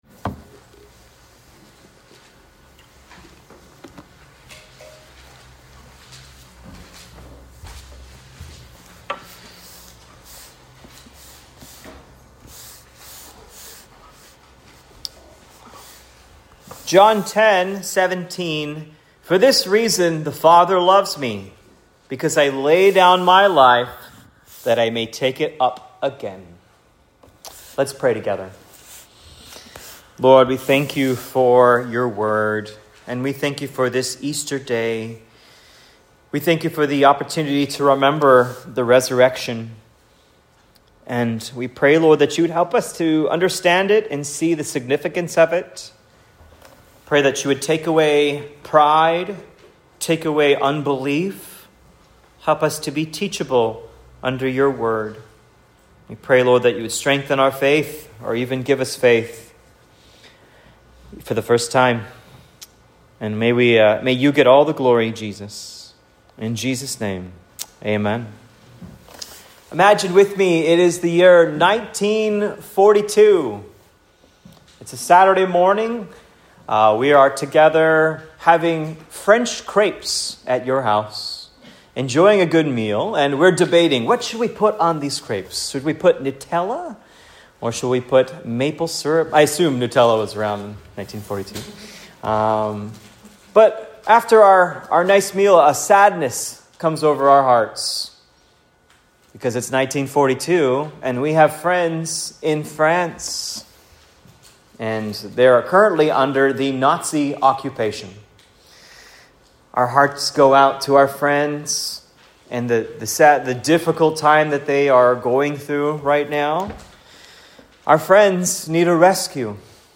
An Easter Sermon: Victorious Love (2025)